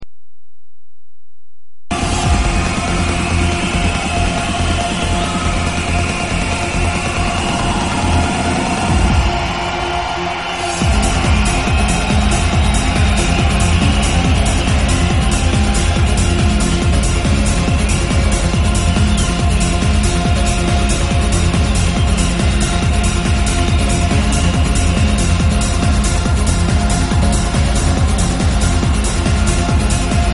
It was shot in Poland